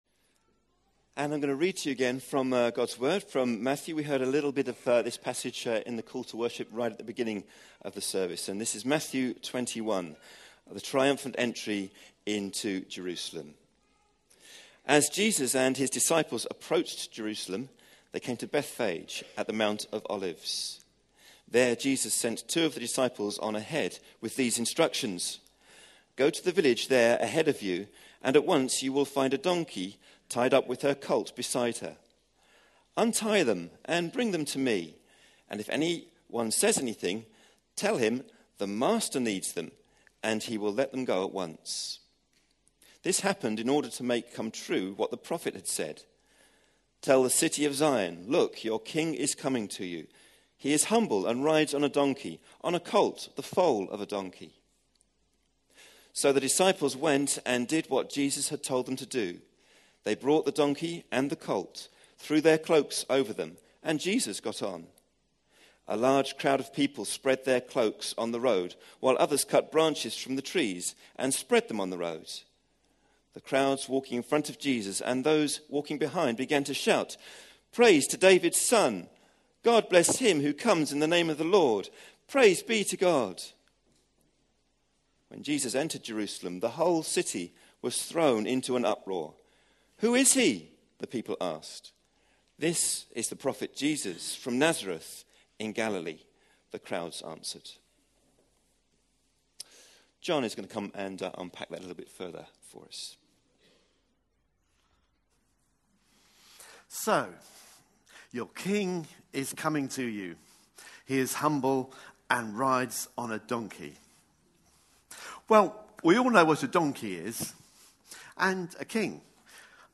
A sermon preached on 20th March, 2016.